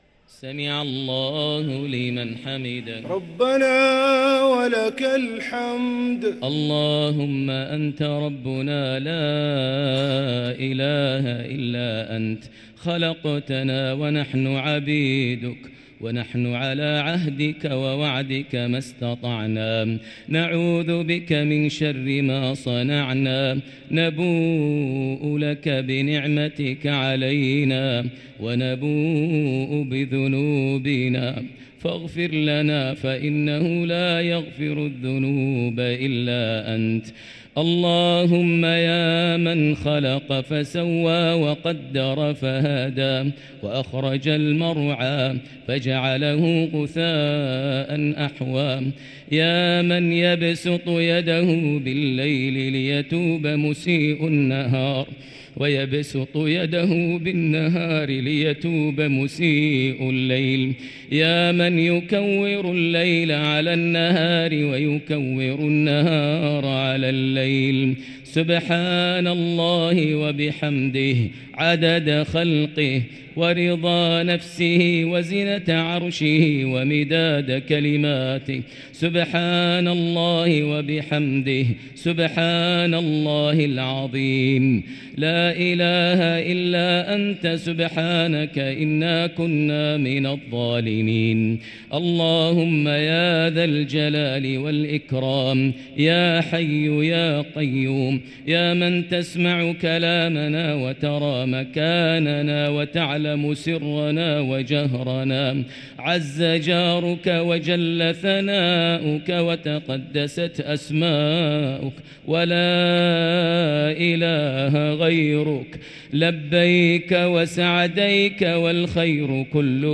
دعاء القنوت ليلة 26 رمضان 1444هـ > تراويح 1444هـ > التراويح - تلاوات ماهر المعيقلي